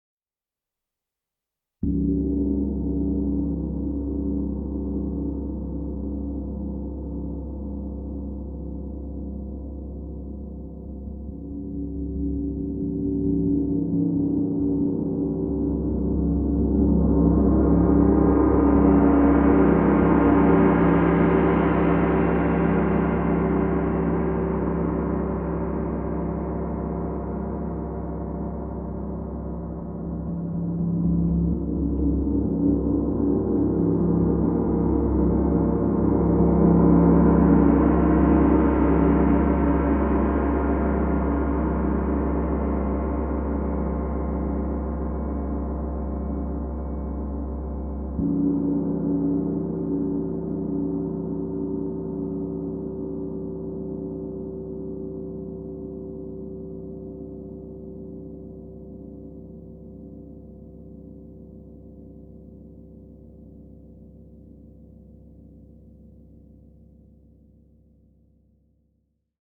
Meinl Sonic Energy 32" Om Gong, 136,10 Hz / C#2, Herzchakra (G32OM)
Sie sind auf den Ton "Om" gestimmt, der eine der heiligsten Silben des Hinduismus ist.
Die immense Größe und Kraft dieses Instruments wird jeden Zuhörer in Ehrfurcht und Staunen versetzen.